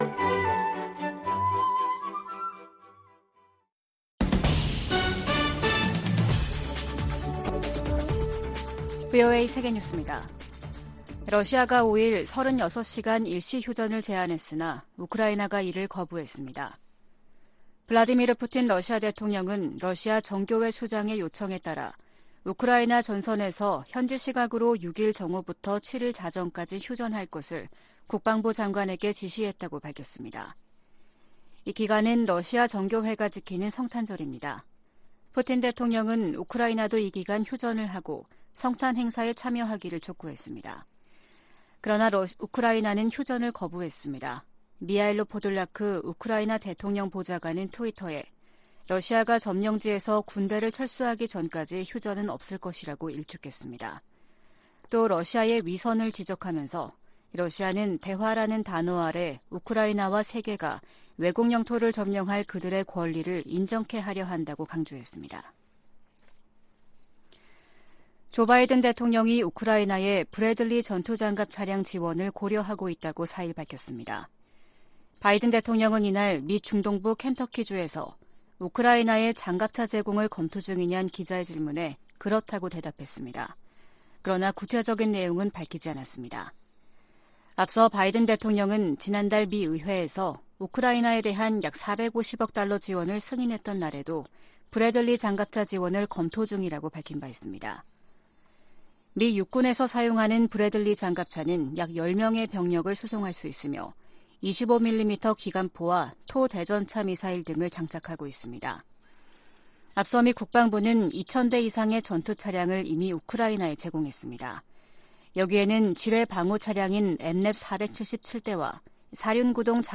VOA 한국어 아침 뉴스 프로그램 '워싱턴 뉴스 광장' 2023년 1월 6일 방송입니다. 한국 국가정보원은 북한 무인기의 용산 대통령실 촬영 가능성을 배제할 수 없다고 밝혔습니다. 미국은 북한 정권의 핵 무력 추구를 면밀히 주시하며 미한일 군사협력 강화를 계속 모색할 것이라고 백악관 고위관리가 밝혔습니다.